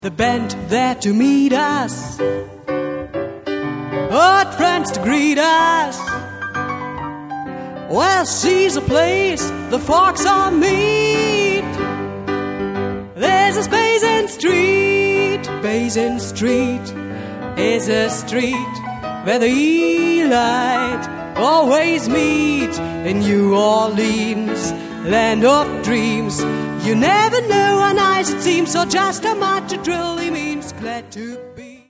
Stimme
Klavier